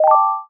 back-button-click.wav